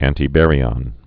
(ăntē-bărē-ŏn, ăntī-)